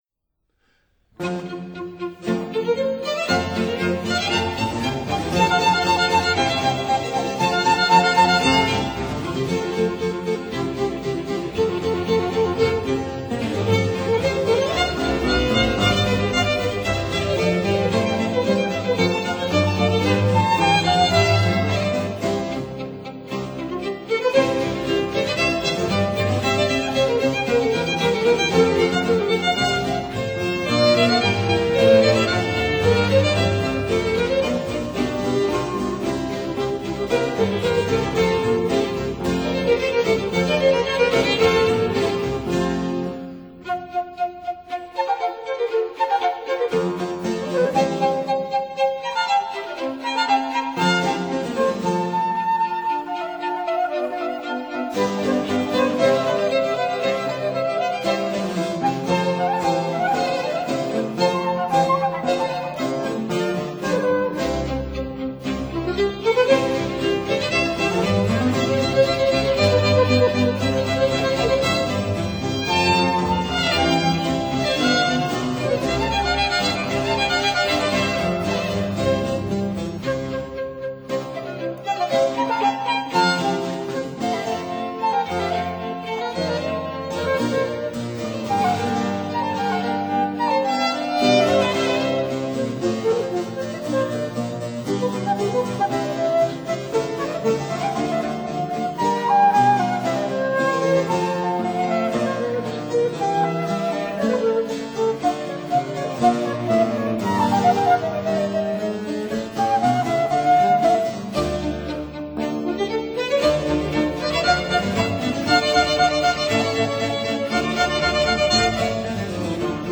Flute Concerto G-Dur